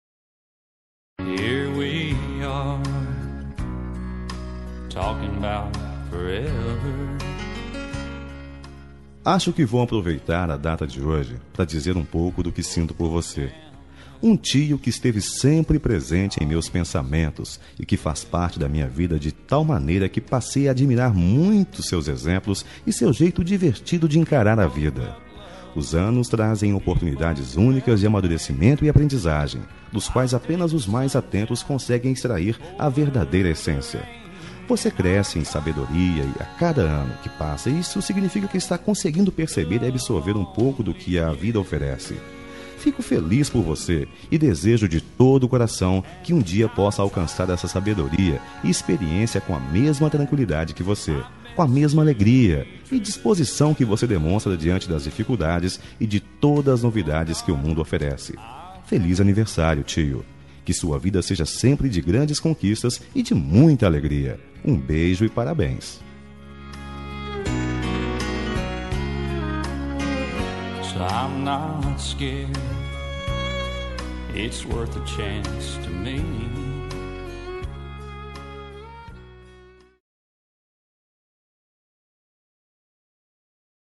Aniversário de Tio – Voz Masculina – Cód: 912
912-tio-masc.m4a